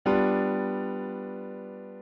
ドミナントセブンスの構成
適度な安定感と不安定感を併せ持っている。
c3-G7-balanced.mp3